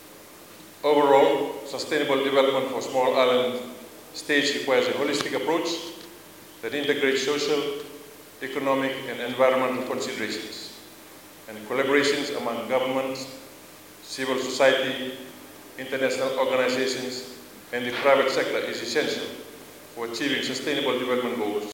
Speaking this morning during the Forum on Sustainable Development of SIDS, Radrodro proposed short-term programs in Chinese universities to enhance expertise in mediation, counseling, inclusive education, and Information Technology.